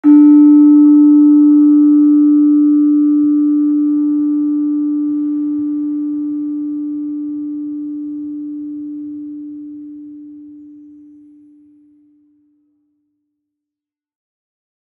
Gender-2-D3-f.wav